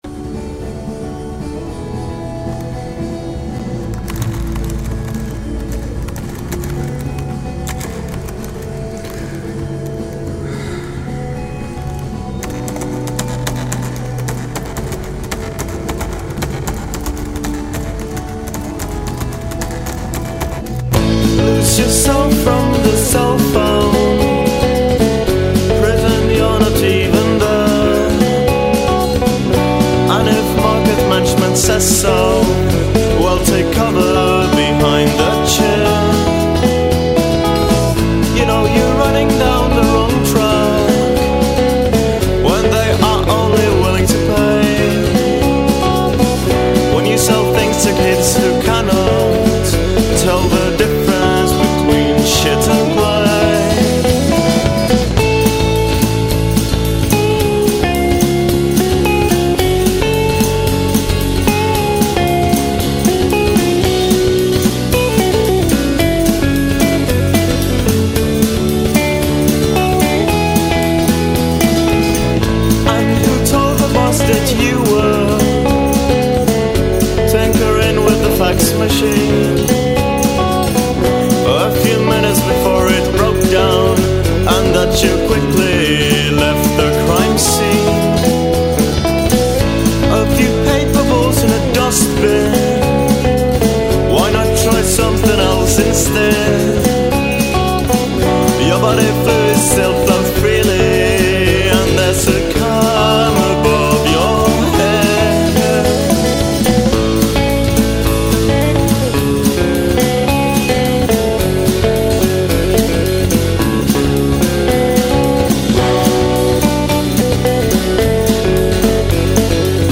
(demo)